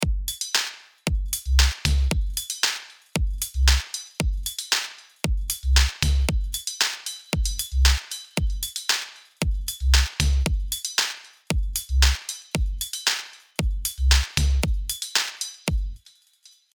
Electronic. Ударные
В общем вожусь несколько дней над ударными, выходит как-то криво и вяло.